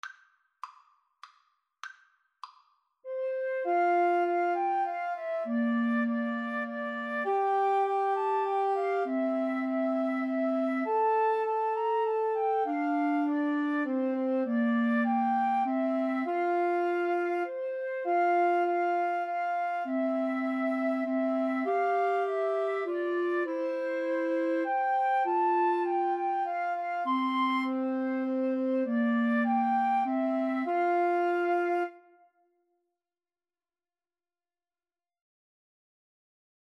Moderato
3/4 (View more 3/4 Music)